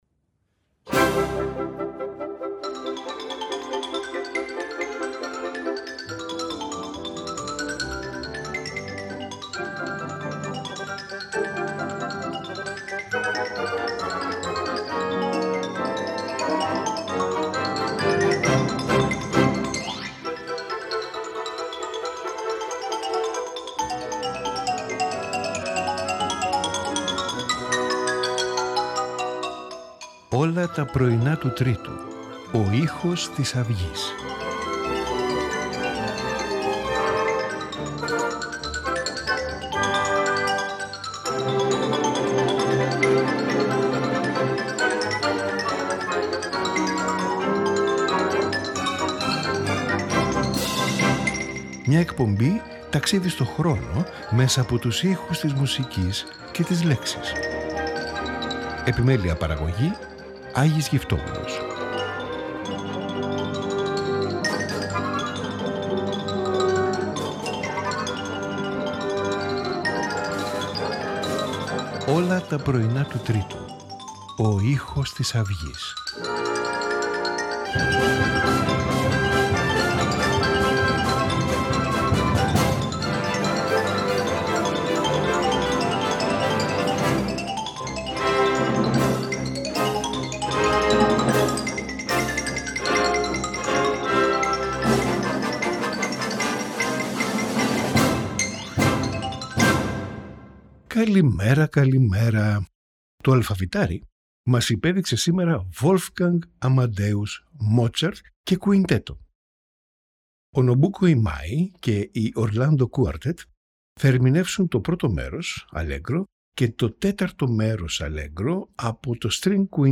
W.A. Mozart – String Quintet No. 6 in E♭, K.614Thomas Dyke Acland Tellefsen – Grand Polonaise in C♯ minor, Op.18J.S. Bach – Brandenburg Concerto No. 5, BWV 1050